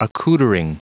Prononciation du mot accoutering en anglais (fichier audio)
Prononciation du mot : accoutering